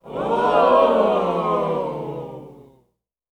一群人喊哦音效_人物音效音效配乐_免费素材下载_提案神器
一群人喊哦音效免费音频素材下载